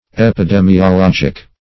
epidemiologic \ep`i*de`mi*o*log"ic\, epidemiological